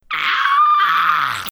hurt_sound.wav